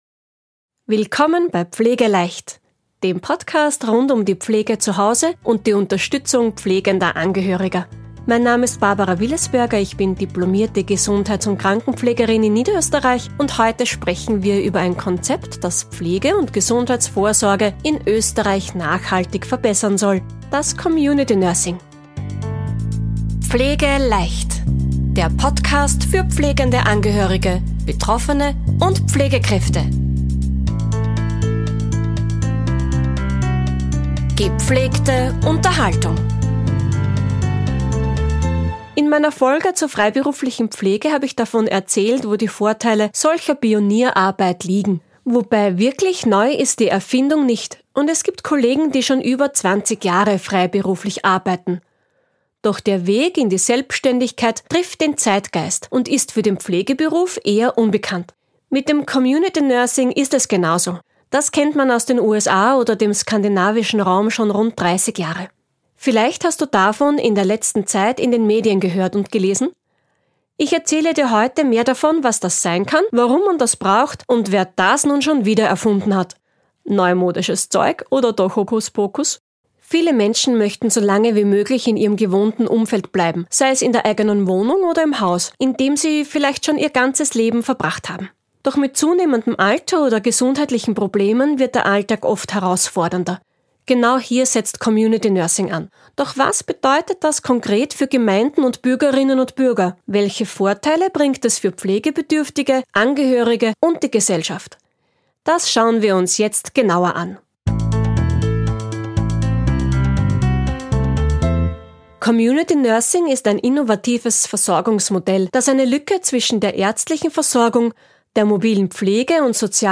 Sprecherin: